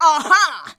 AHA.wav